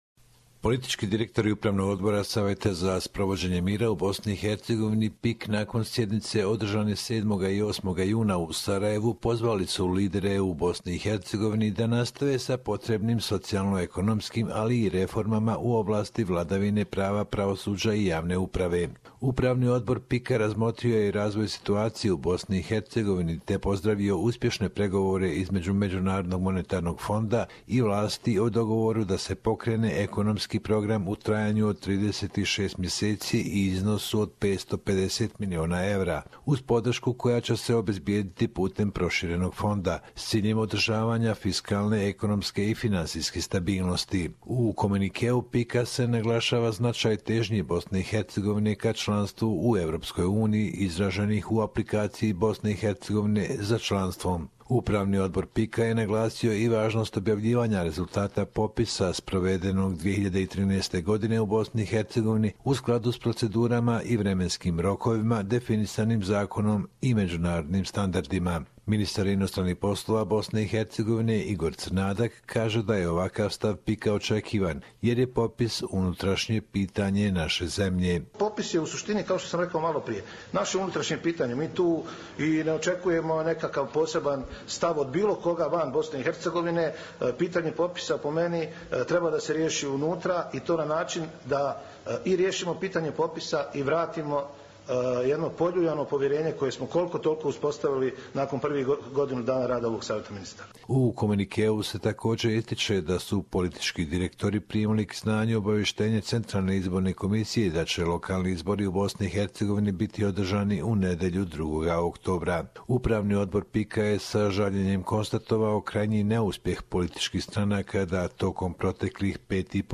Report from Bosnia and Herzegovina